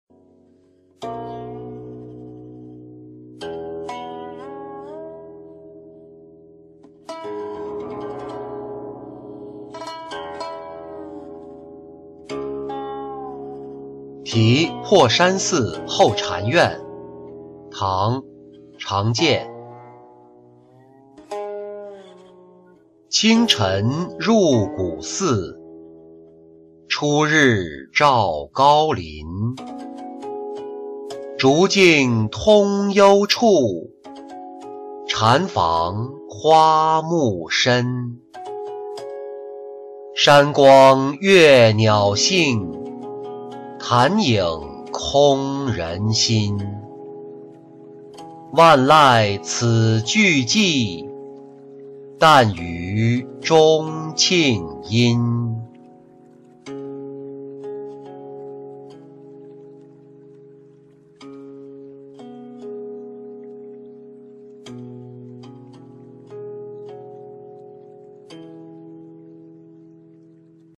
题破山寺后禅院-音频朗读